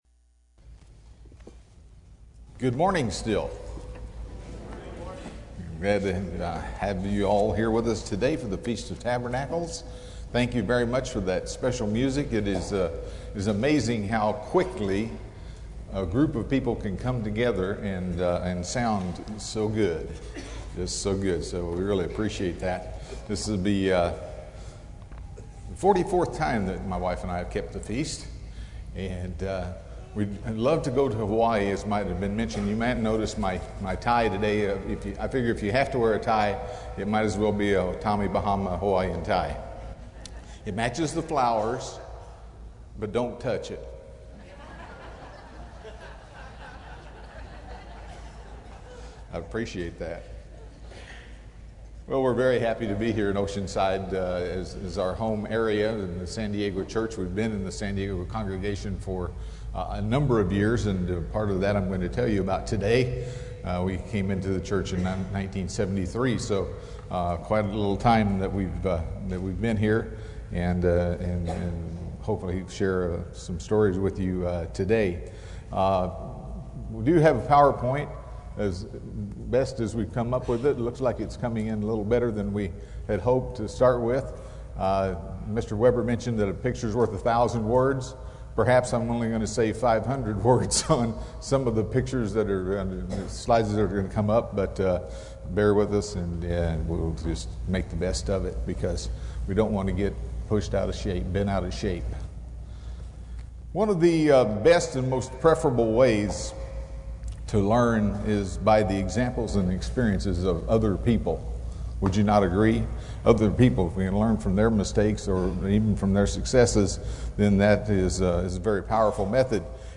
This sermon was given at the Oceanside, California 2017 Feast site.